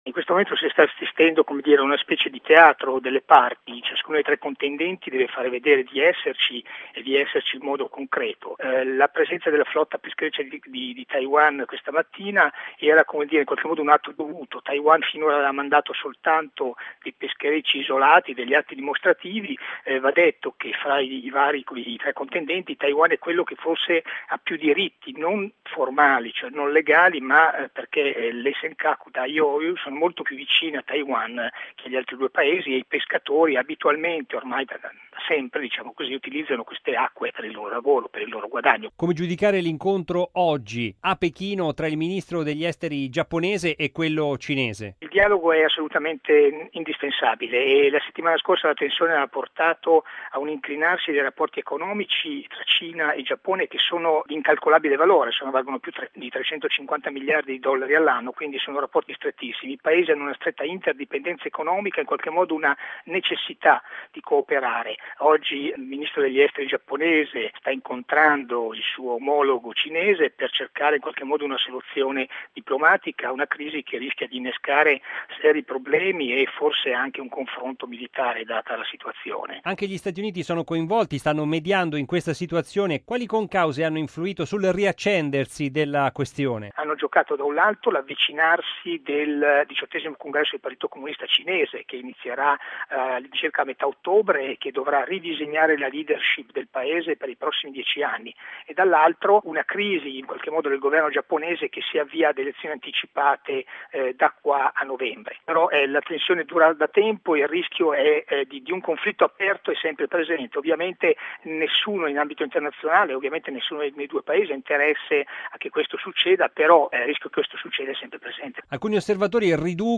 ha intervisto